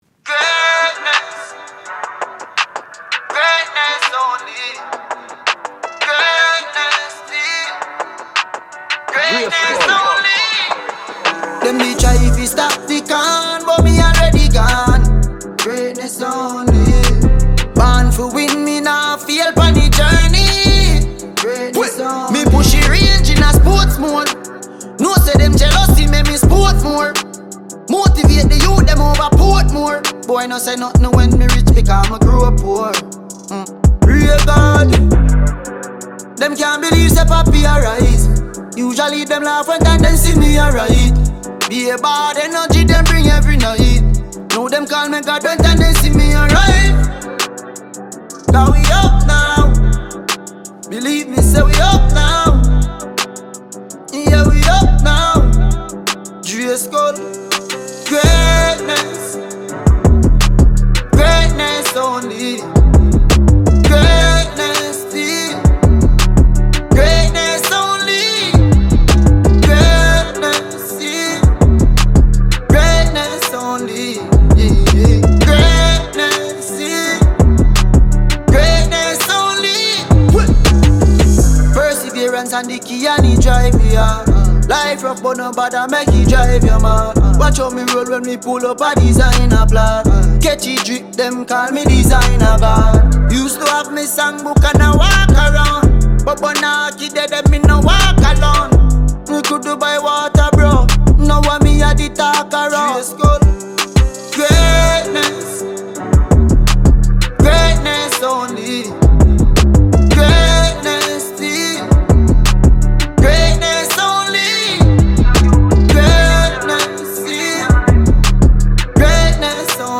Multiple award-winning Jamaican reggae-dancehall musician